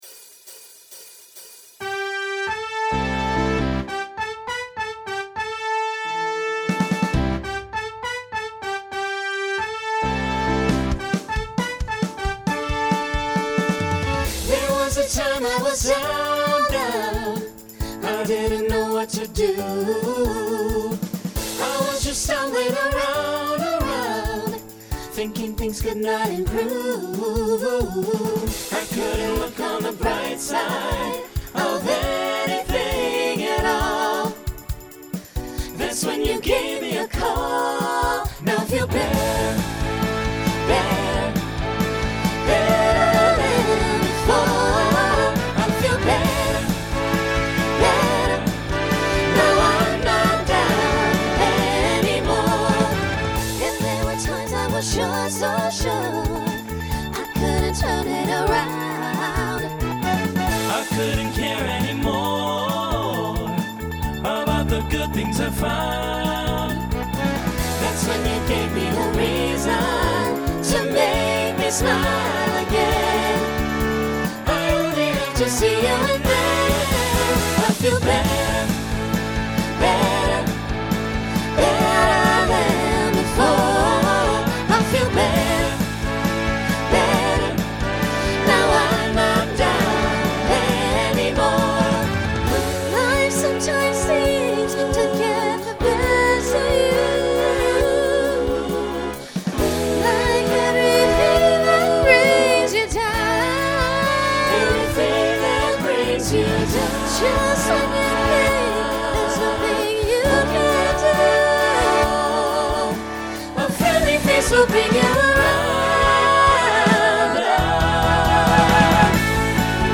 Genre Pop/Dance Instrumental combo
Opener Voicing SATB